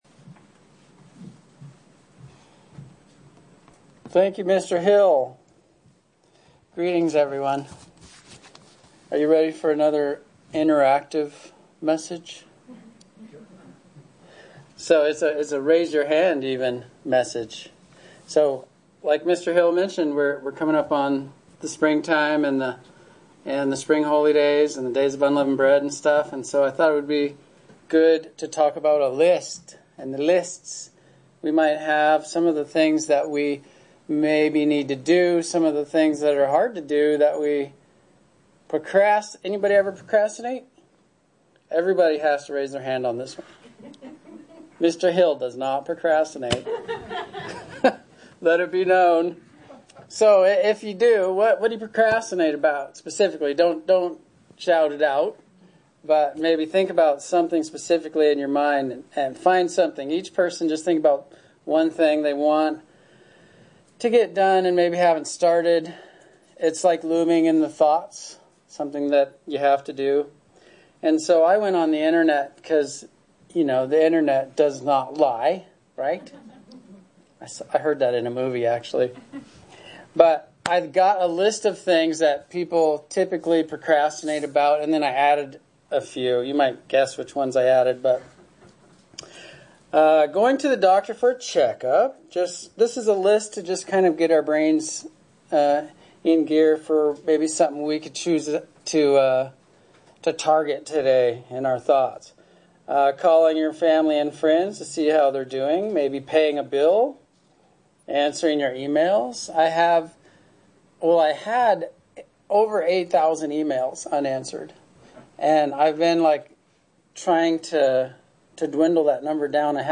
Sermons
Given in Central Oregon Medford, OR